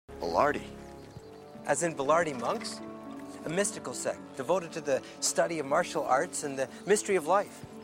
Do přílohy dávám anglické audio asi 8 sekund zvuku.